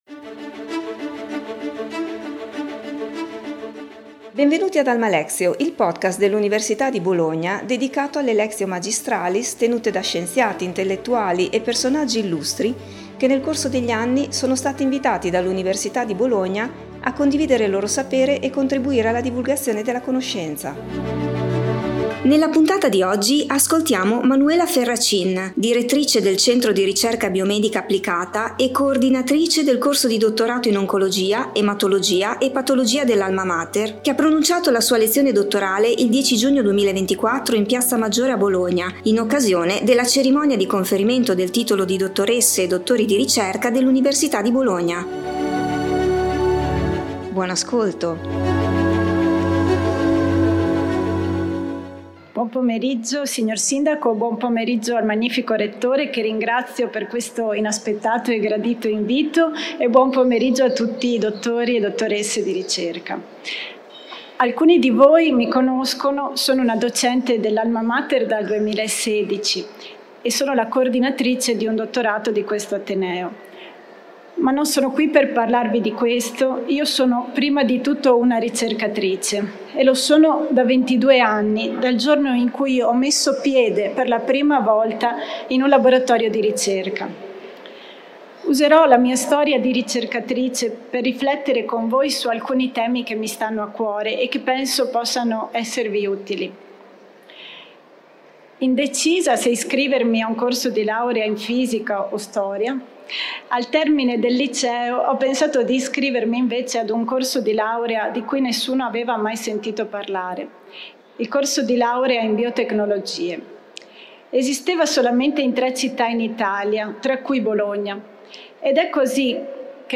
lezione dottorale
in piazza Maggiore a Bologna